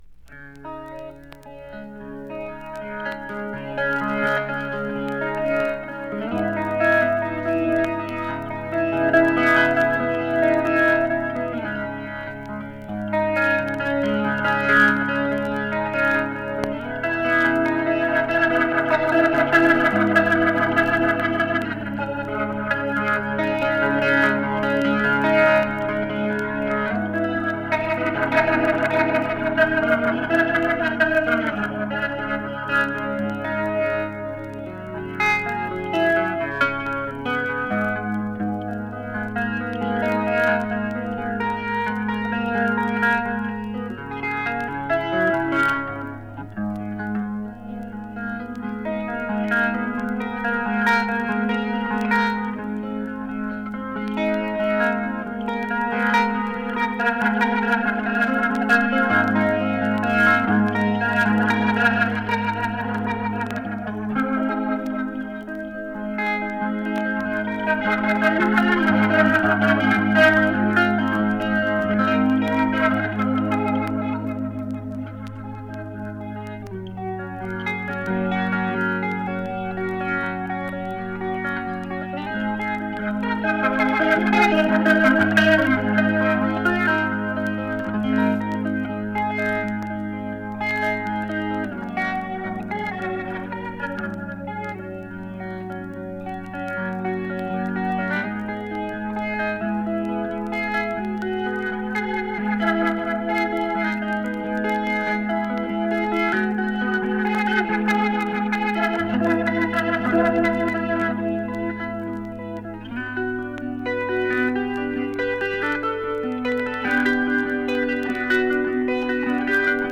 Psych / Prog